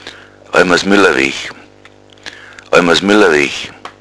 Lateinische Sprachrelikte im bayerischen Dialekt, Flurnamen